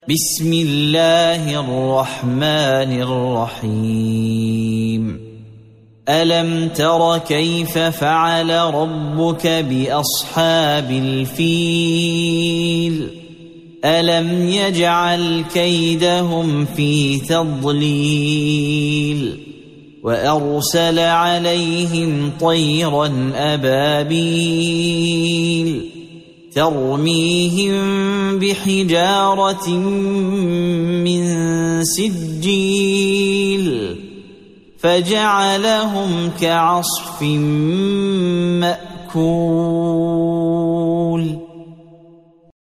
سورة الفيل مكية عدد الآيات:5 مكتوبة بخط عثماني كبير واضح من المصحف الشريف مع التفسير والتلاوة بصوت مشاهير القراء من موقع القرآن الكريم إسلام أون لاين